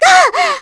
Dosarta-Vox_Damage_kr_02.wav